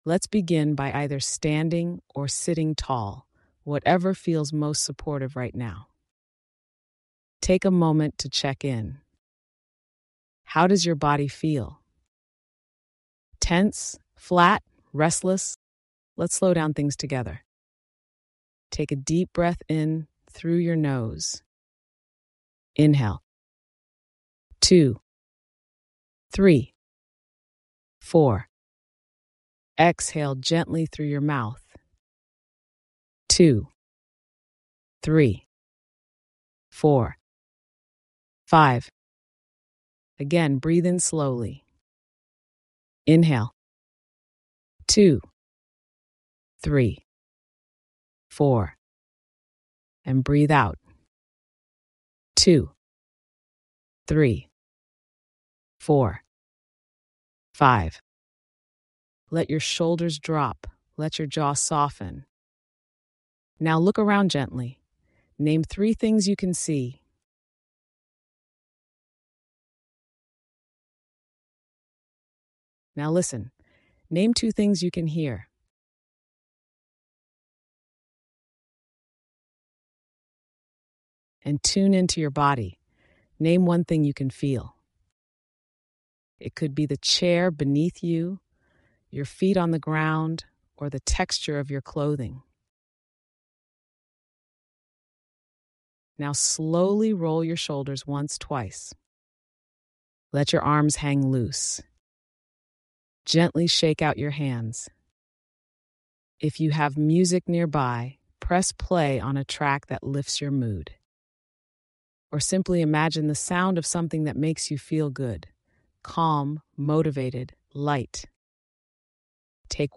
Join this 2-minute guided reset to shift your emotional energy using your breath, body, and a bit of sound.